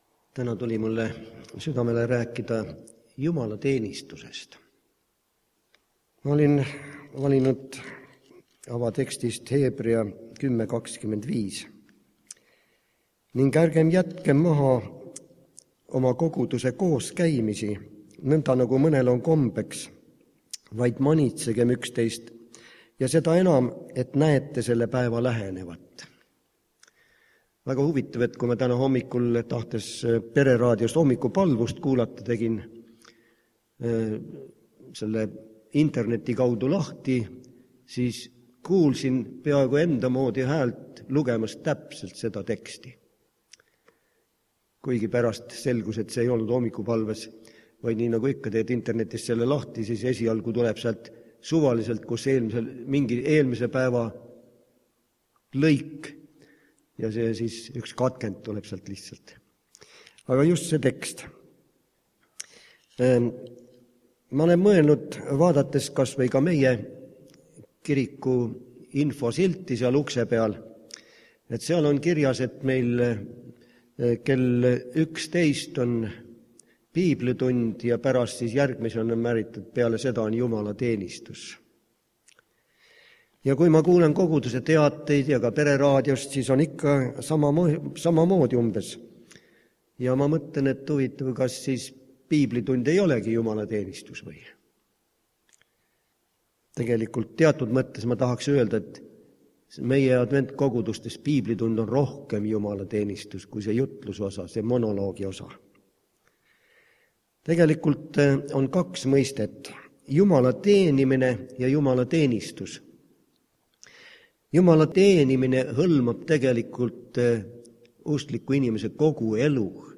Muusikaliseks vahepalaks kolm laulukest
Jutlused